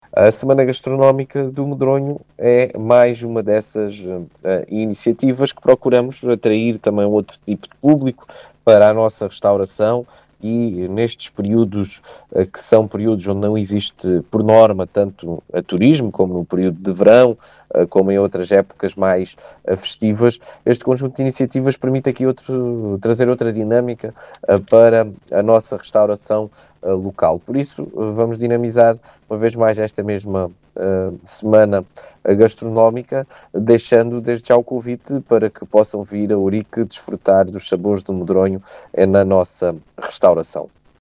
Oiça as declarações de Marcelo Guerreiro, presidente da CM de Ourique